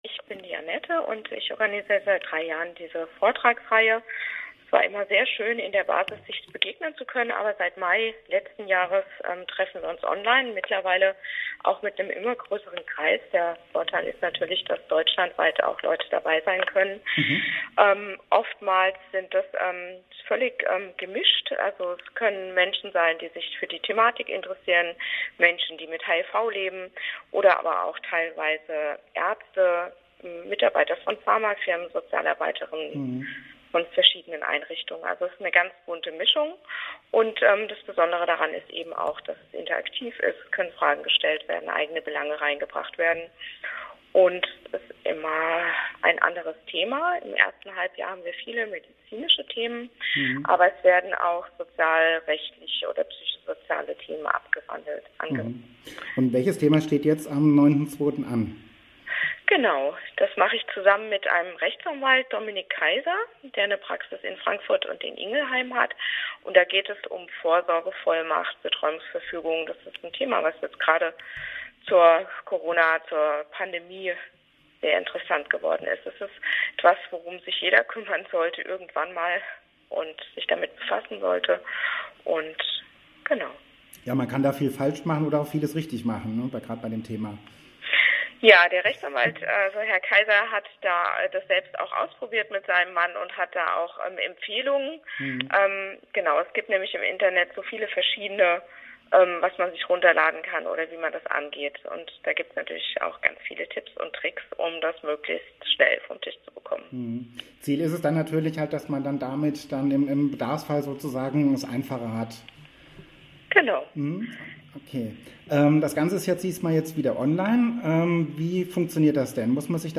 Frankfurter Vorträge der Aidshilfe Ffm zum Thema Vorsorgevollmacht